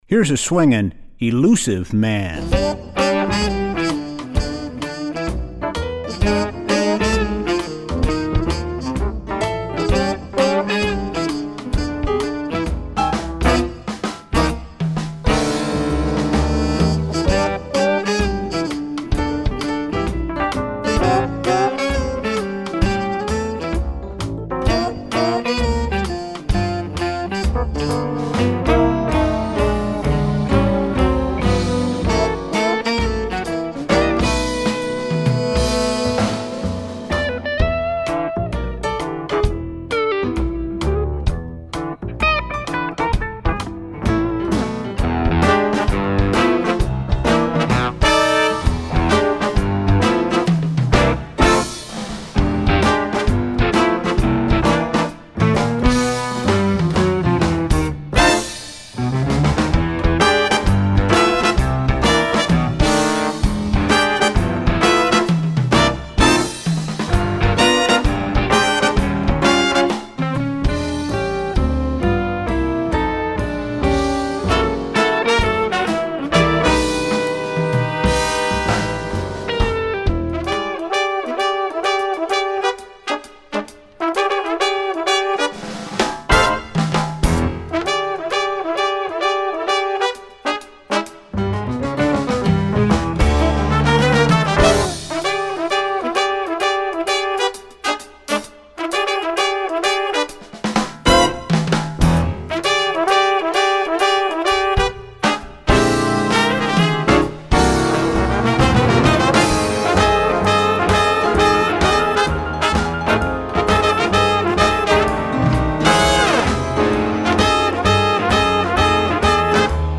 Voicing: Combo